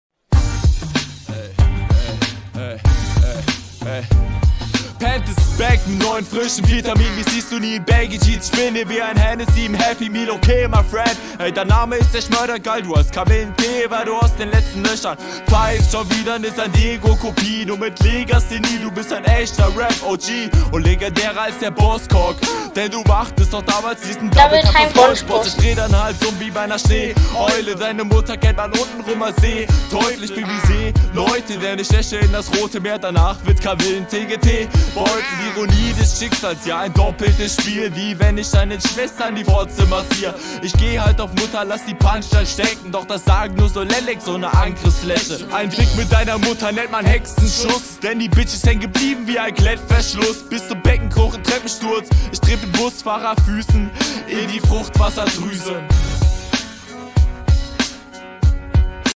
Stimmeinsatz ist ziemlich nice, Reimsetzung teilweise sehr stark, manchmal aus meiner Sicht aber ein bisschen …
Flow ist ganz cool vor allem für Bronze.